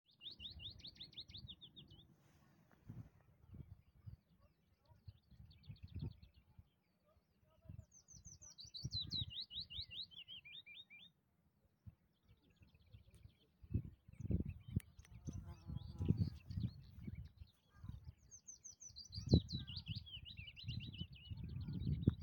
Ein Foto war nicht möglich – sie war tausendmal schneller als ich – dafür nahm ich aber ihren Gesang auf (zu hören ist neben der Klappergrasmücke ein Fitis).
Voegel-auf-Borkum-2-Fitis-Klappergrasmuecke.mp3